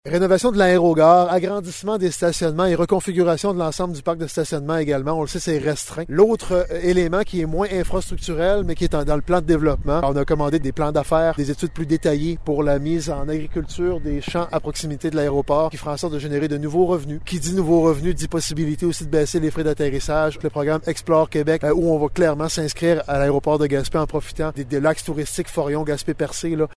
Le maire de Gaspé donne des précisions par rapport aux travaux :